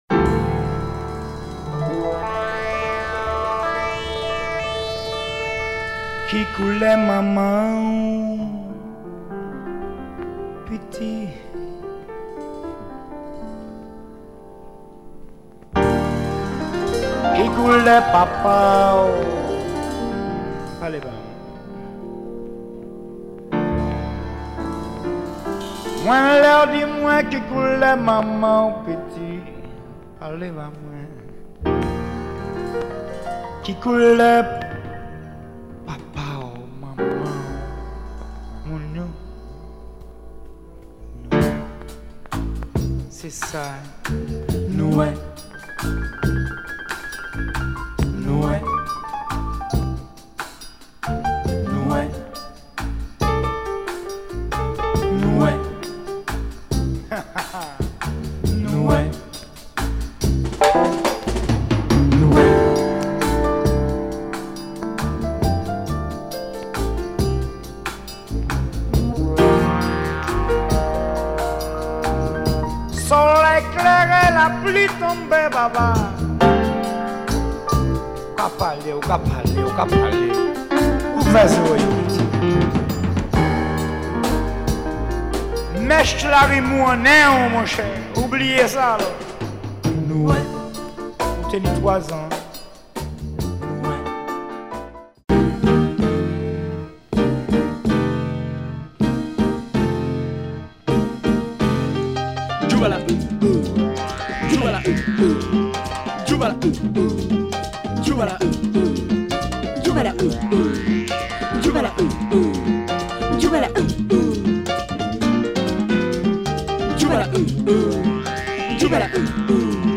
Superb Caribbean soul jazz & groovy beguine.